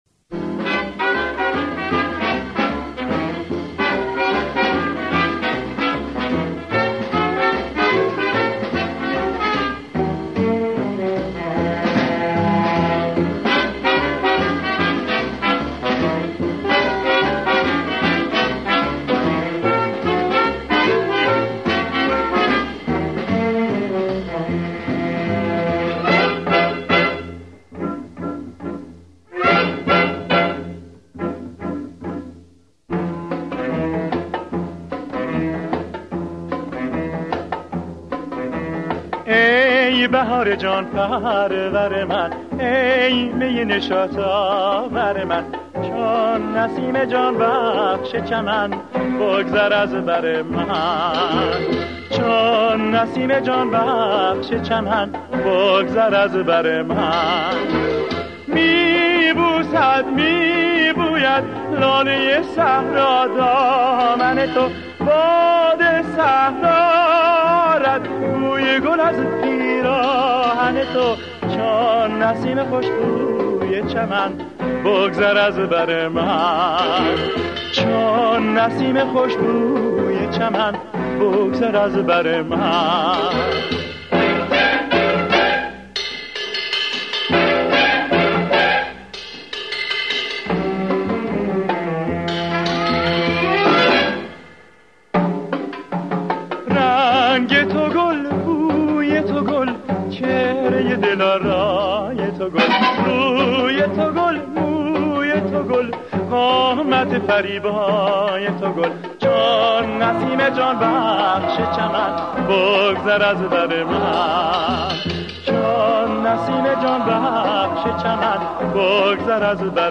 این آهنگ لطیف و آرام